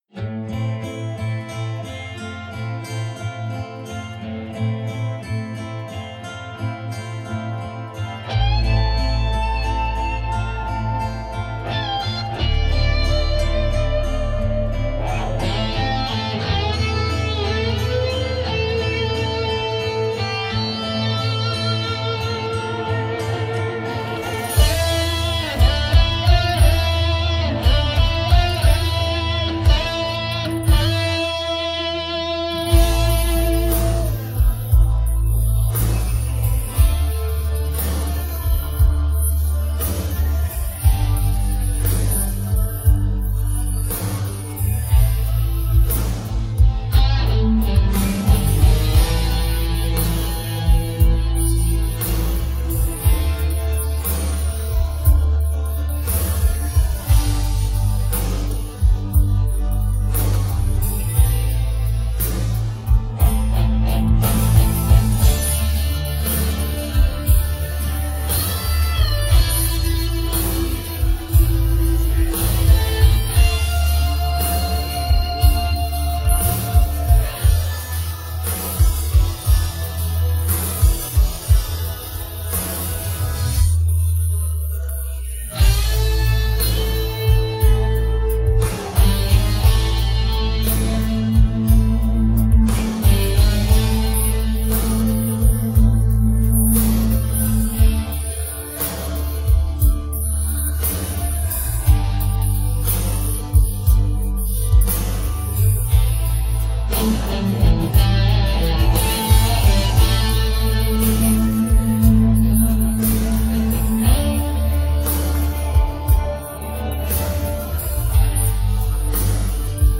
Sing with Us